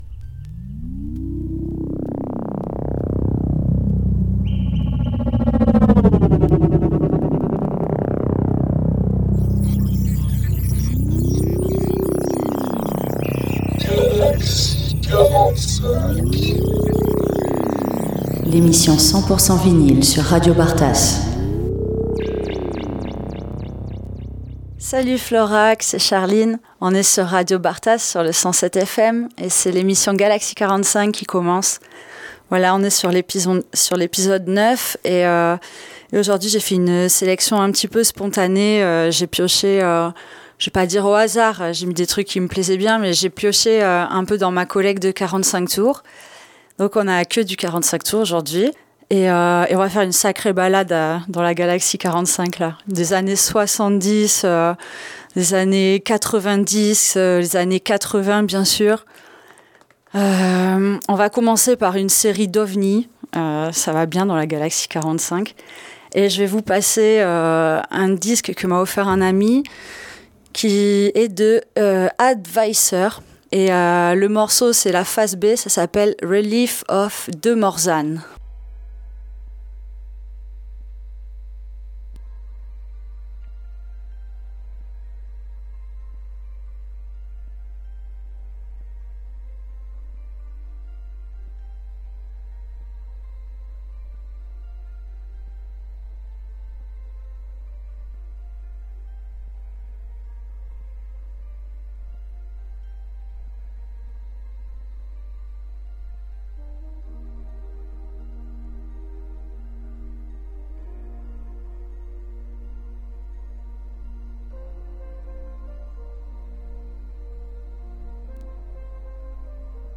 Pour ce 9e épisode justement, l’émission sera uniquement avec des 45 tours et vous fera voyager des années 70 aux 90.